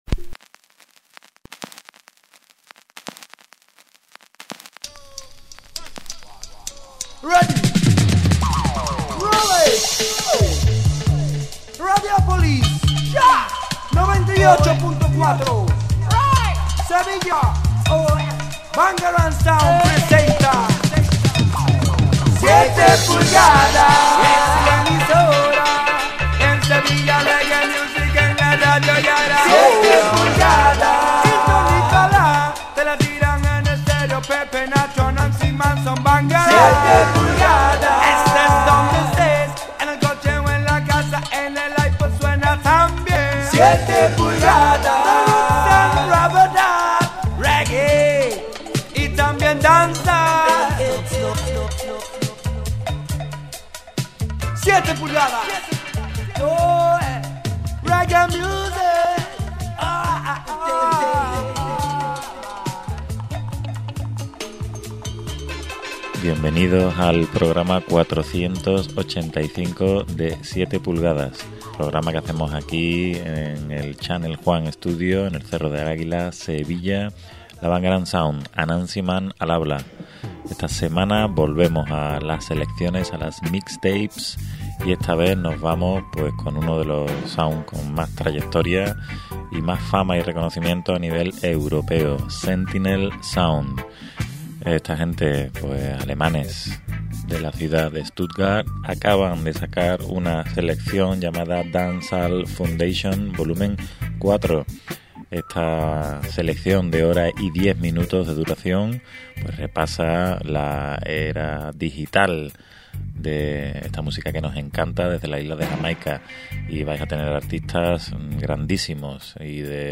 dancehall digital
Mixtape